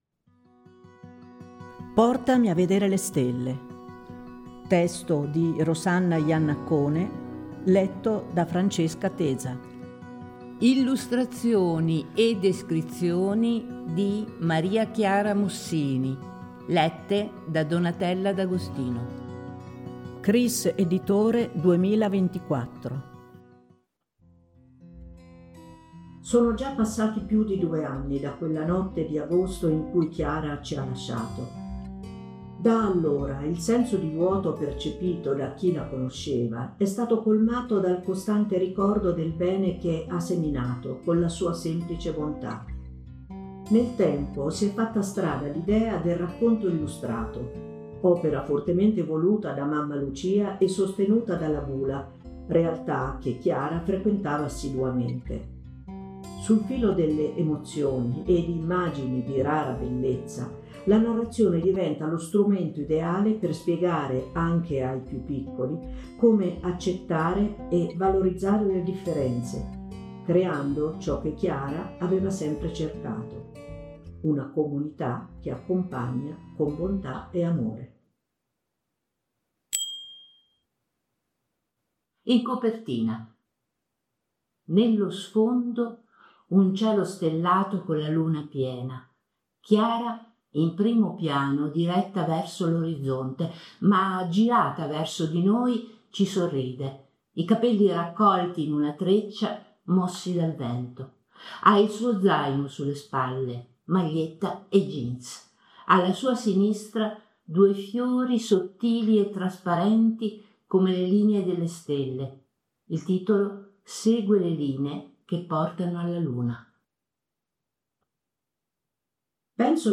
E così al racconto si sono affiancate le descrizioni delle illustrazioni per potere vedere il libro con l’udito, grazie al Cepdi .